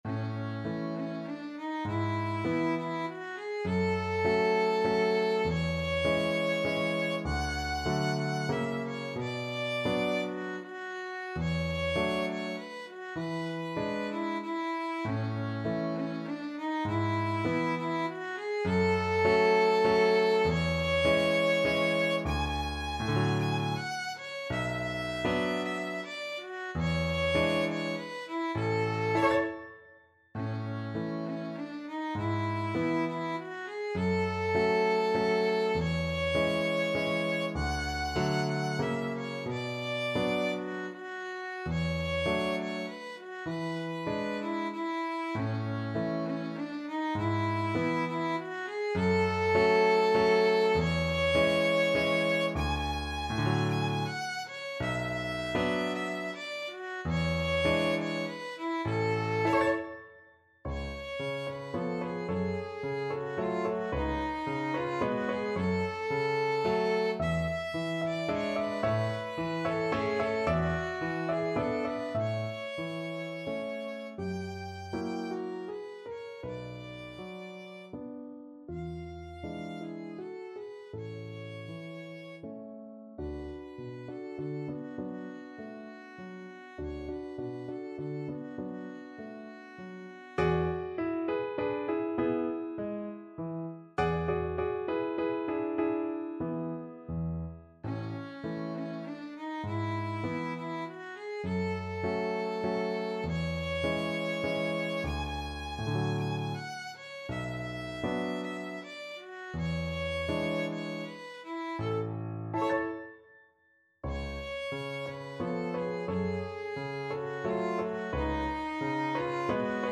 Violin
3/4 (View more 3/4 Music)
A major (Sounding Pitch) (View more A major Music for Violin )
~ = 100 Tranquillamente
merikanto_valse_lente_op33_VLN.mp3